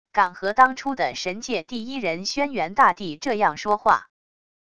敢和当初的神界第一人轩辕大帝这样说话wav音频生成系统WAV Audio Player